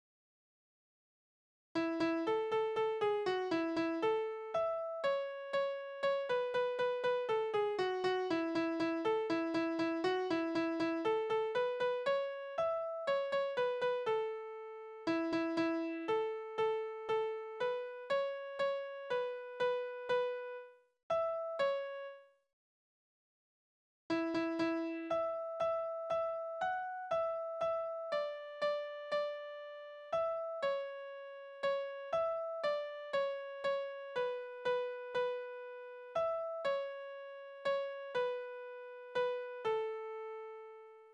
Dialoglieder: Der Grobschmied und sein studierender Sohn
Tonart: A-Dur
Taktart: 4/4, 3/4
Tonumfang: große None
Besetzung: vokal